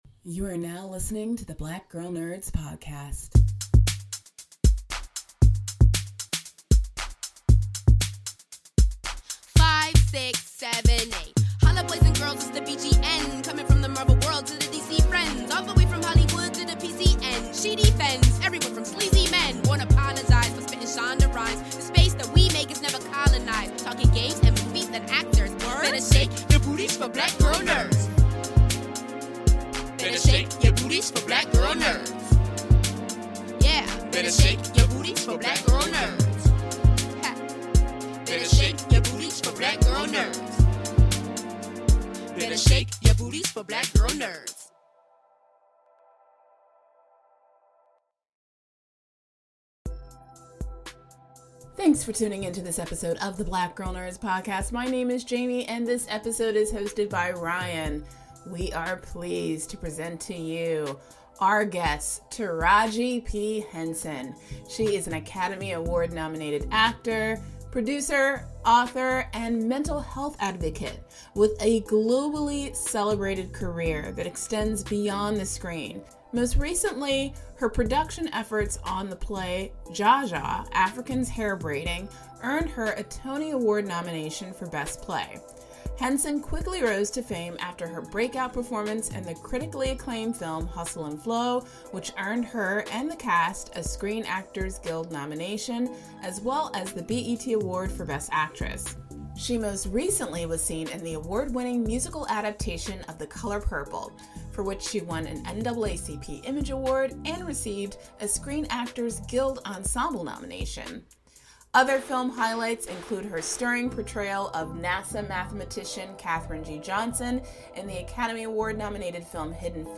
In this week's episode of the Black Girl Nerds podcast, we welcome actor and author Taraji P. Henson.